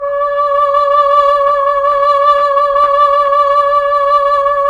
Index of /90_sSampleCDs/Roland LCDP09 Keys of the 60s and 70s 1/KEY_Chamberlin/VOX_Chambrln Vox